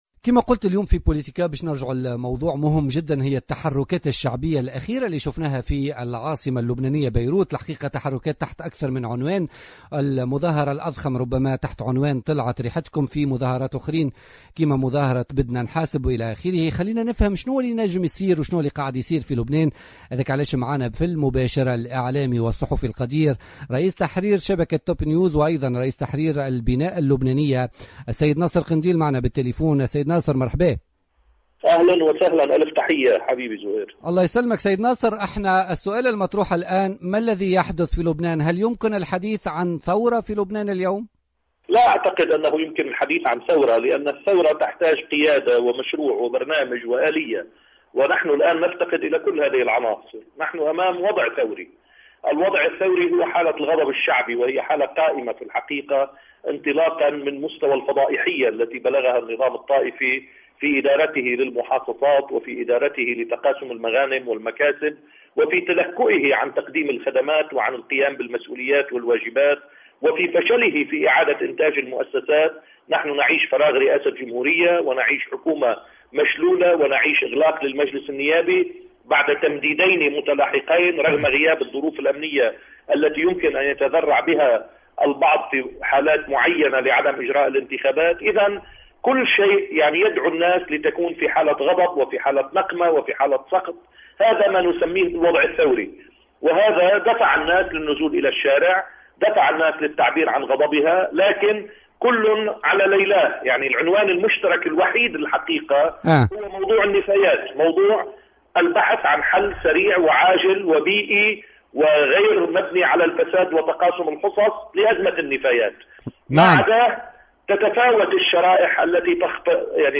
أكد ناصر قنديل رئيس تحرير شبكة توب نيوز ورئيس تحرير جريدة البناء اللبنانية في تصريح لجوهرة أف أم في برنامج بوليتكا اليوم الجمعة 28 أوت 2015 أن ما يحدث في لبنان مؤخرا من تحركات شعبية يعبر عن حالة من الغضب الثوري والسخط للمطالبة بحل لأزمة النفايات التي اكتسحت البلاد ولا يمكن الحديث عن ثورة باعتبار أن الثورة تستوجب قيادة ومشروعا وآلية وهو ما يفتقده لبنان الآن على حد تعبيره.